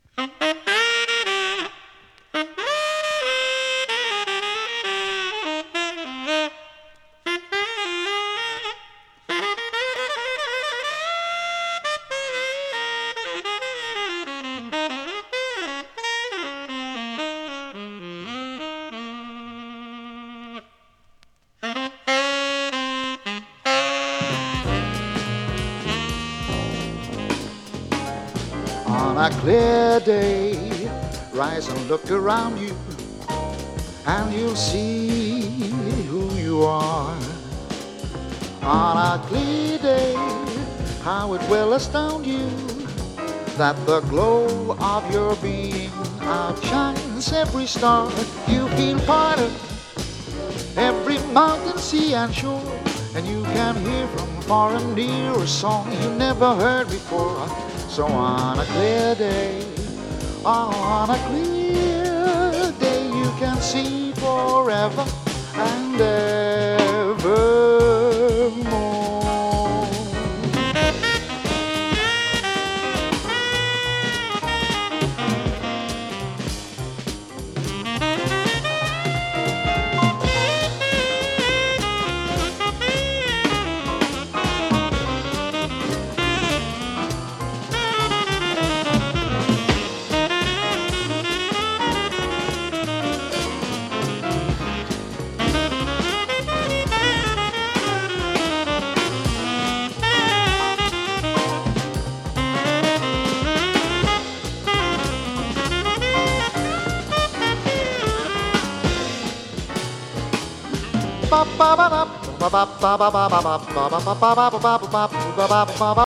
スウェーデンのボーカリストによる最高傑作。
ジャジーなアレンジが素晴らしい大人気盤です！